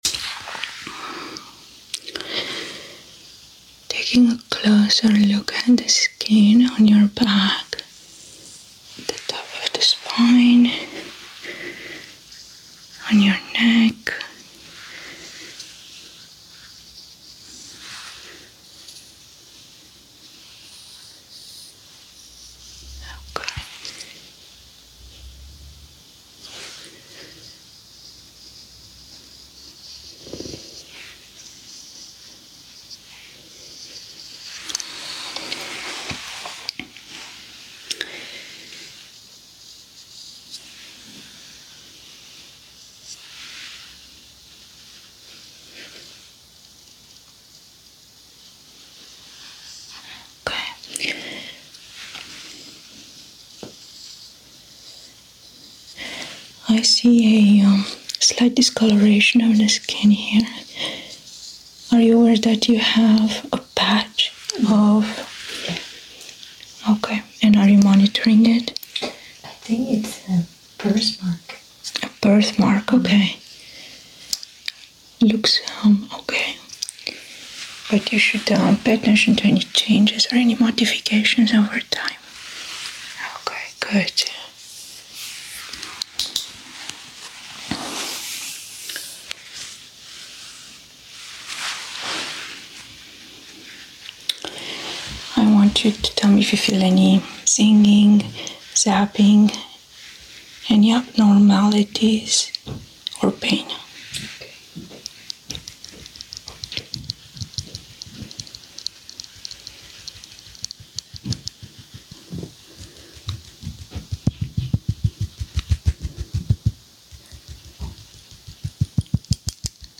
ASMR Back Inspection to Relax sound effects free download